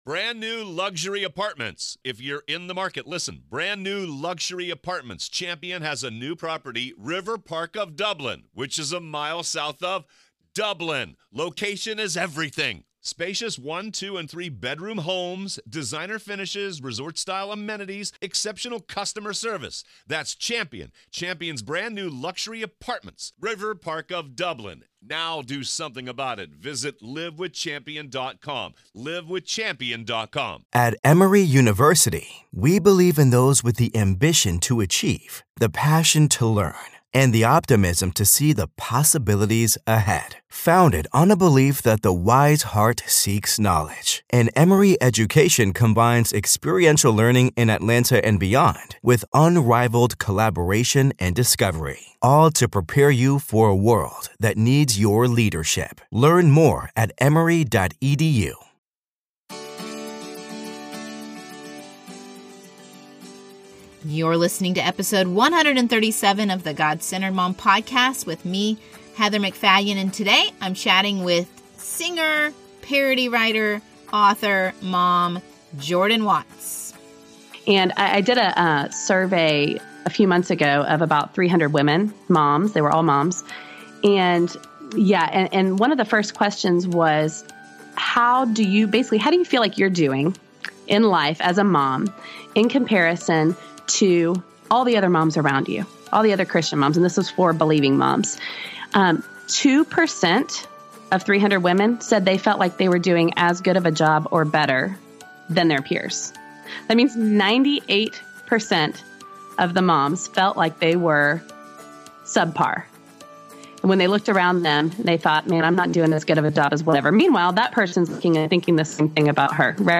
interviews guests discussing the topic of staying God-centered...both replacing "me" with "He" and remembering we are centered in Him.